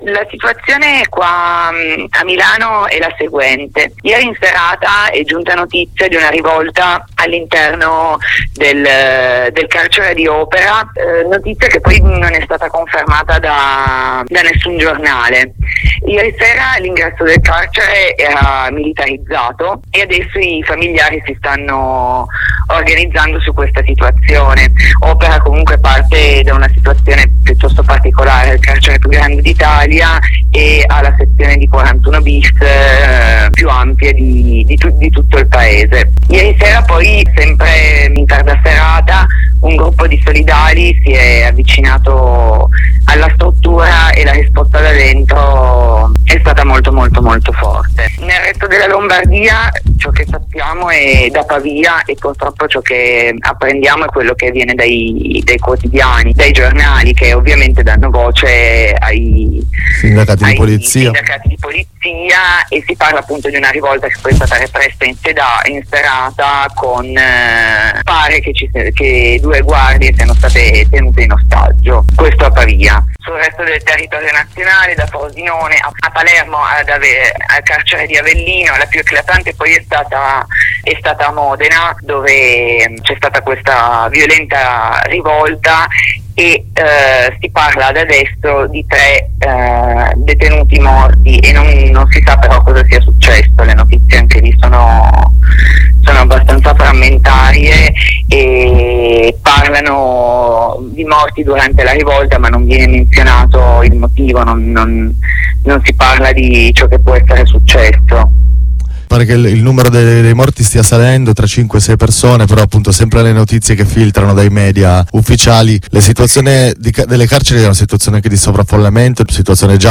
La voce ad una compagna che ci aggiorna da Milano nella mattinata di lunedì 9 marzo 2020